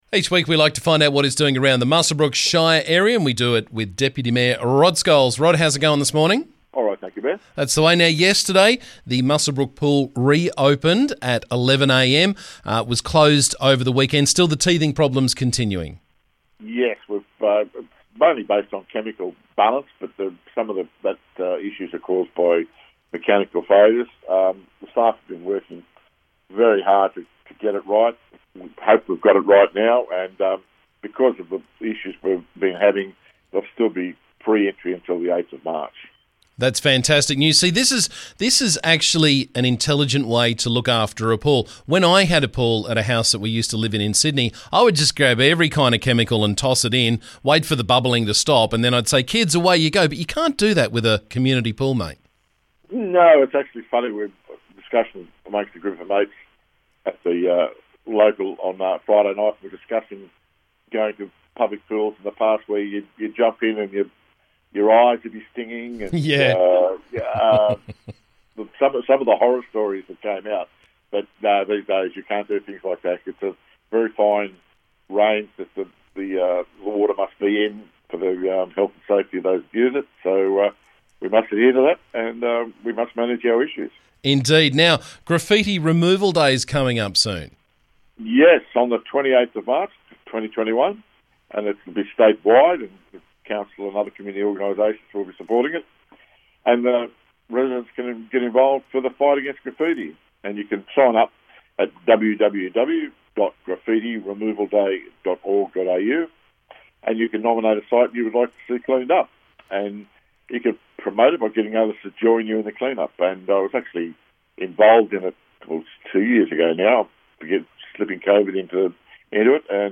Muswellbrook Shire Council Deputy Mayor Rod Scholes joined me to talk about the latest from around the district.